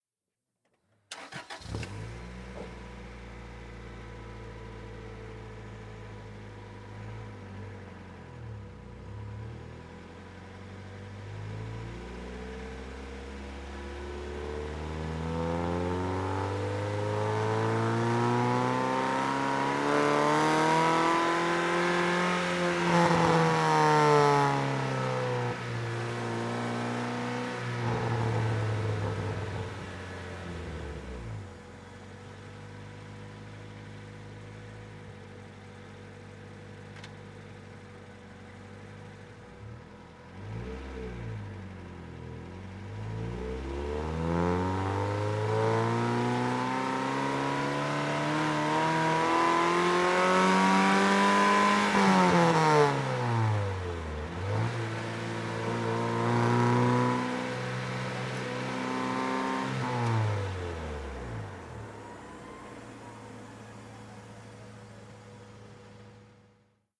Integra onboard - engine, start, idle, medium fast drive